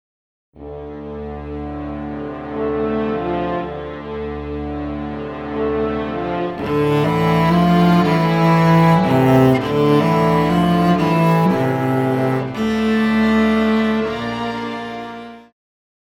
Cello
Band
Instrumental
World Music,Electronic Music
Only backing